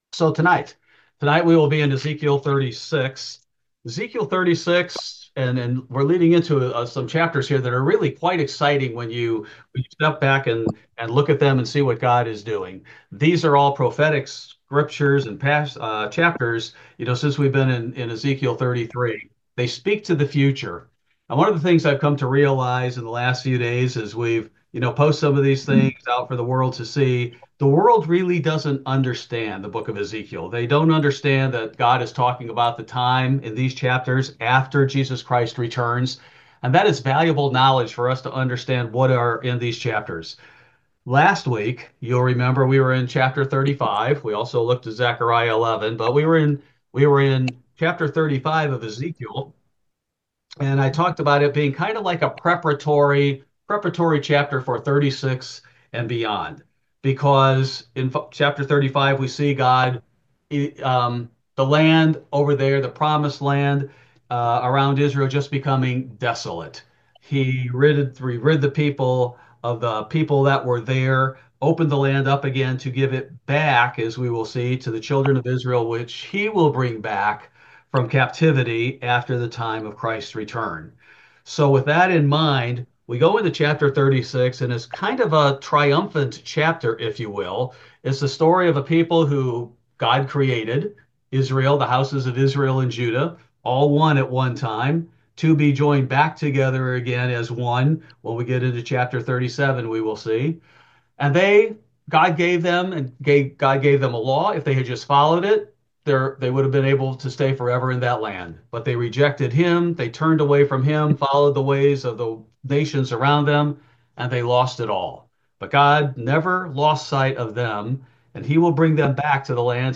Ezekiel Bible Study: February 19, 2025